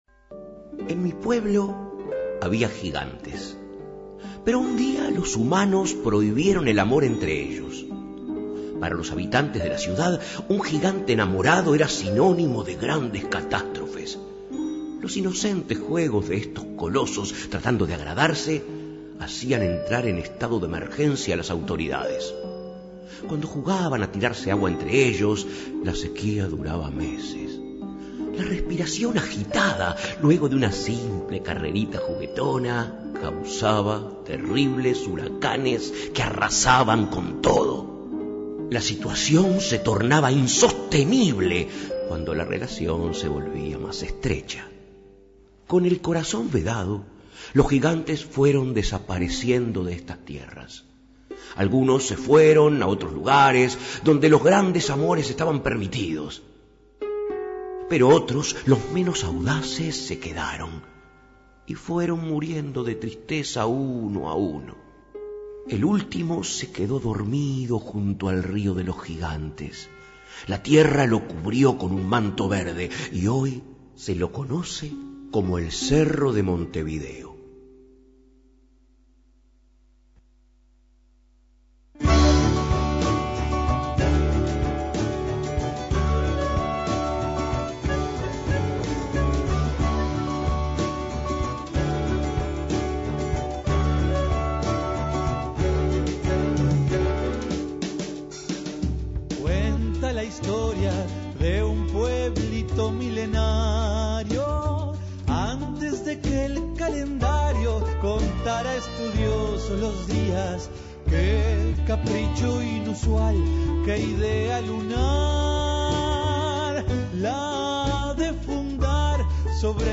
guitarra, composición y voz
piano, sintetizador y composición
contrabajo
clarinete
flauta
viola
batería
violín